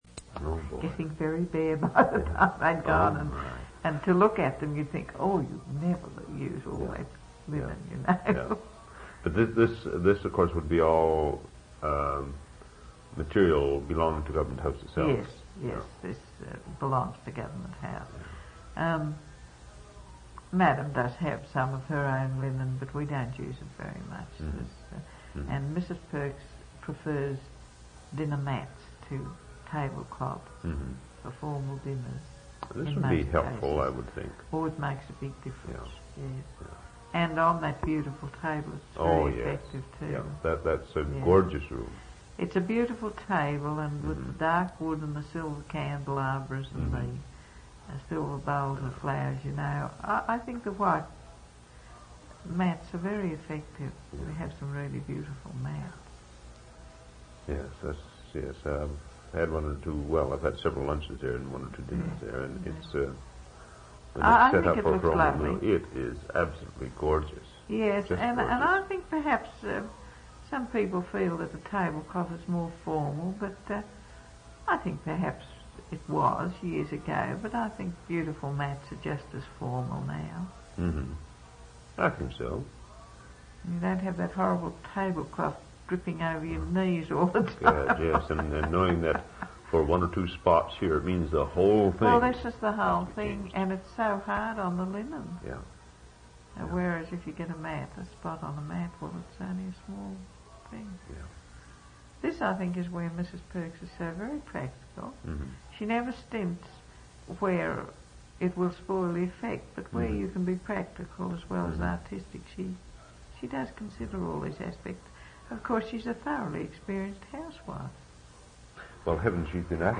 One original sound tape reel (ca. 70 min.) : 1 7/8 ips, 2 track, mono. ; 1 sound cassette copy : standard, mono.
oral histories (literary genre) sound recordings interviews reminiscences